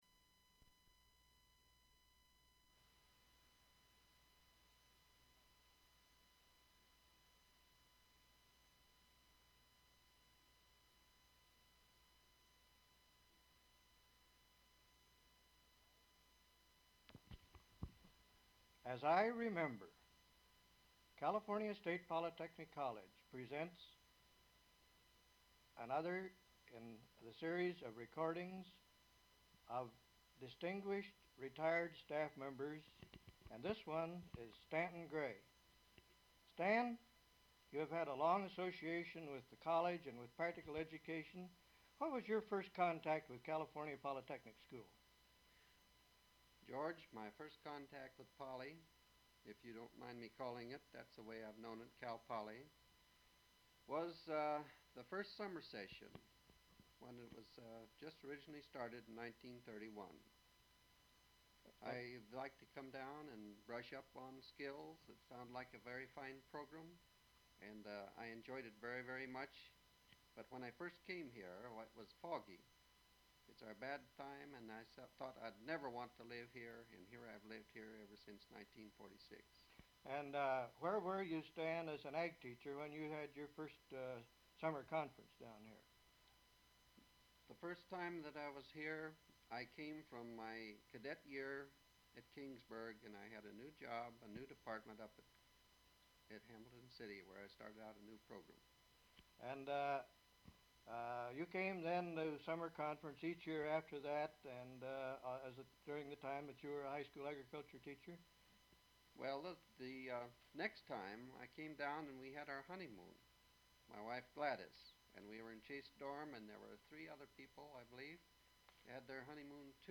interview
Form of original Open reel audiotape